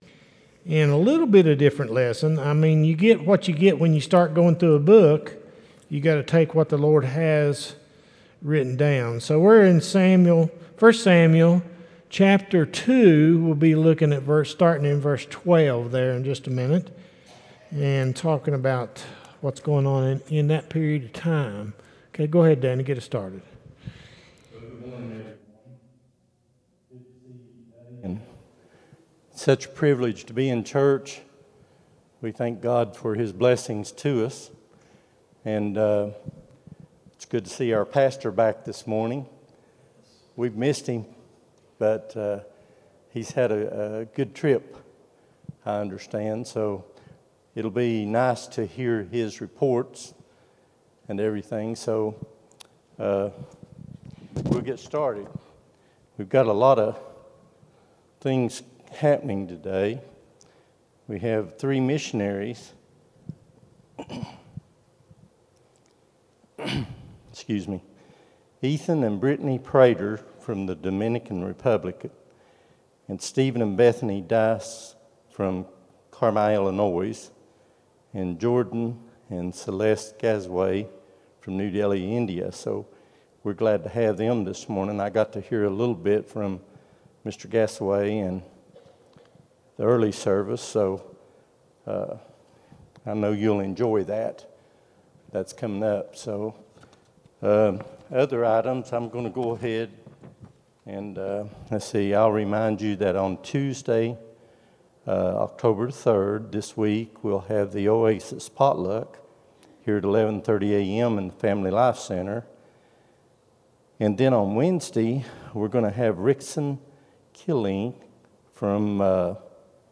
10-01-23 Sunday School | Buffalo Ridge Baptist Church